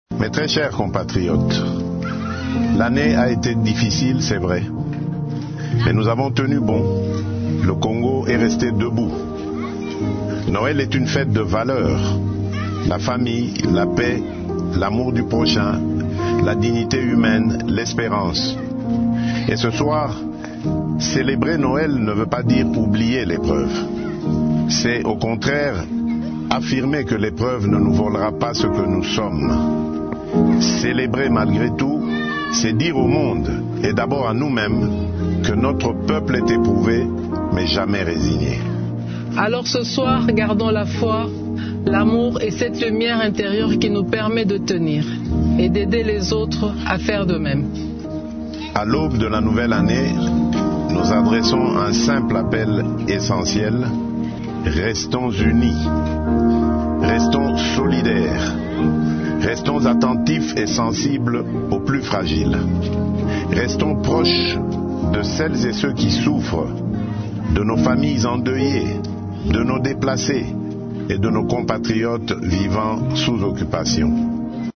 Félix Tshisekedi l’a affirmé mercredi 24 décembre soir dans un message de vœux à la nation en compagnie de son épouse.
Ecoutez le message du couple présidentiel dans cet extrait :